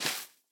minecraft / sounds / block / moss / step5.ogg
step5.ogg